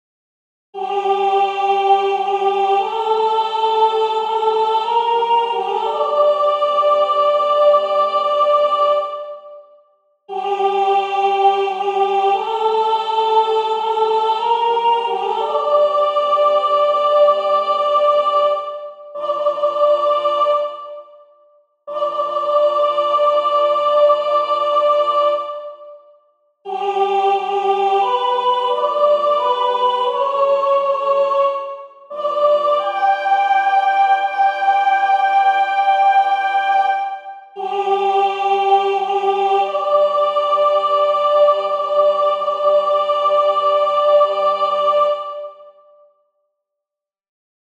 Christmas Cantata - Movement 1 - Part 1 Maestoso — Toronto Choral Society
ChristmasCantataDPinkham+Movement+1+Part+1+Maestoso+-+Soprano+1.mp3